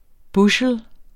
Udtale [ ˈbuɕəl ]